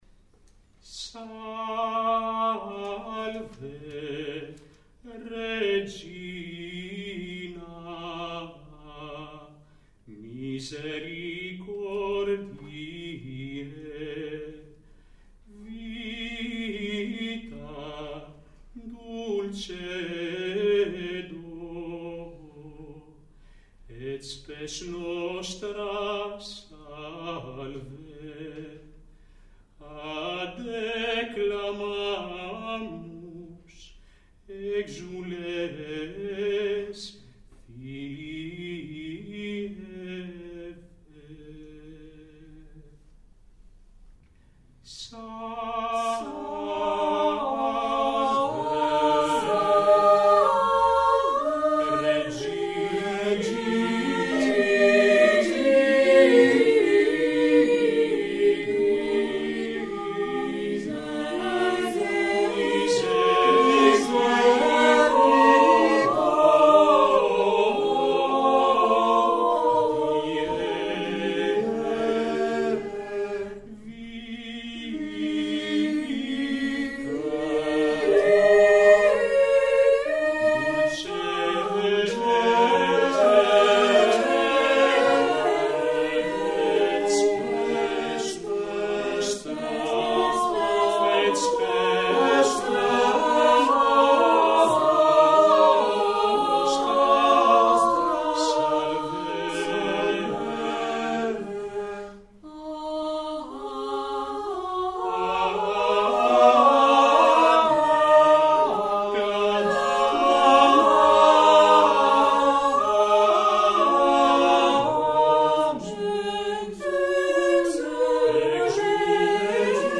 Χωρίς επεξεργασία